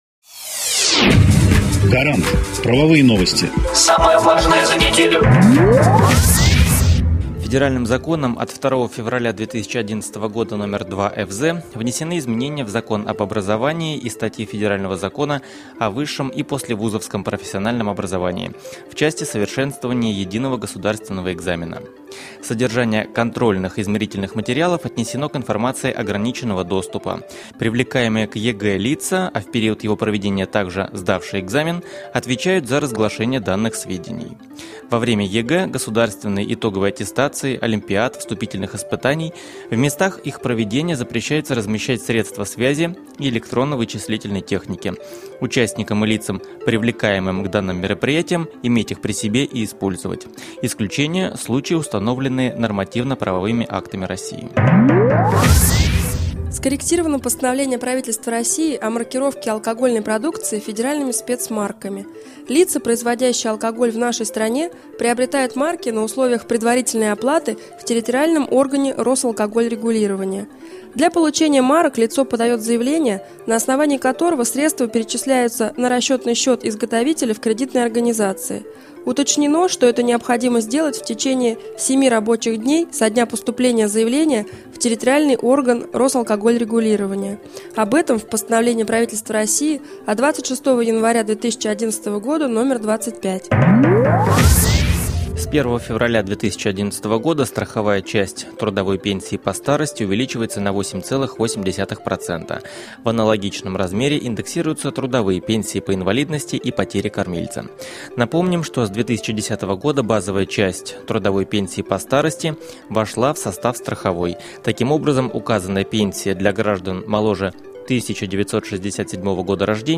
Аудионовости законодательства
Эксперты компании "Гарант" доступно и кратко рассказывают об актуальных законодательных нововведениях за последнюю неделю, акцентируя внимание на самом важном и интересном.